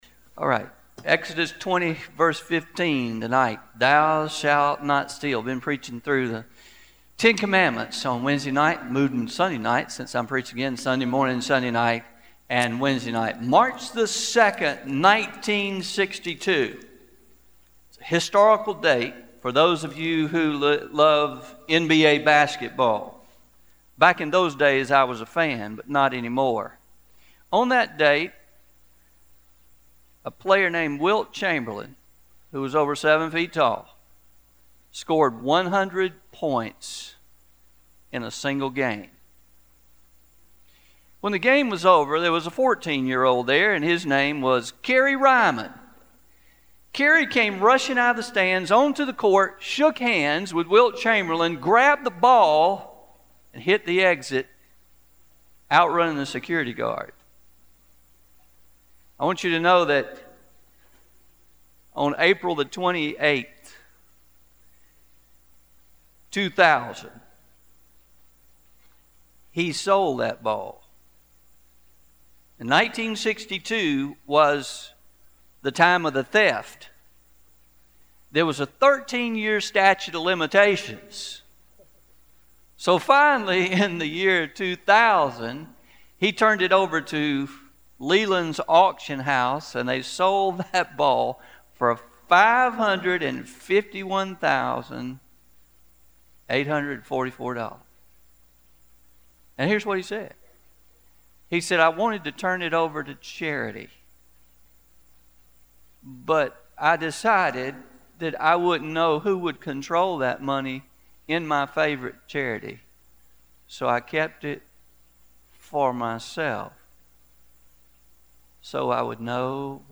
03-10-19pm Sermon – The Sanctity of Property